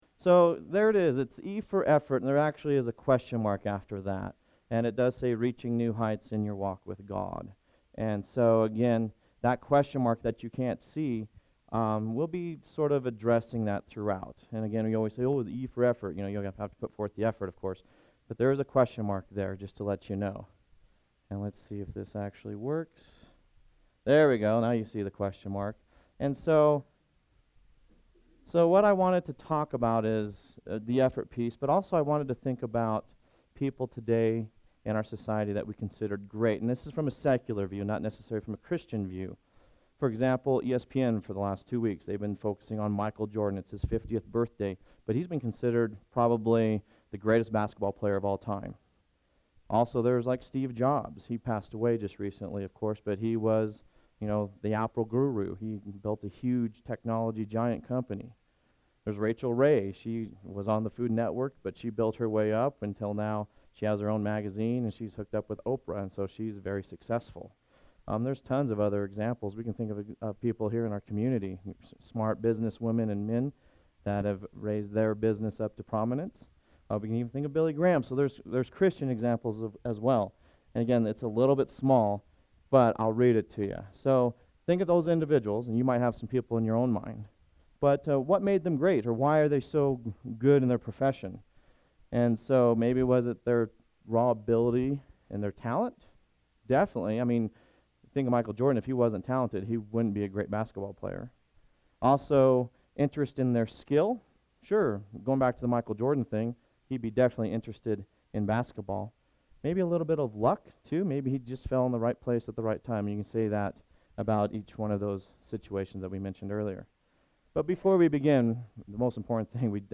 SERMON: E is for Effort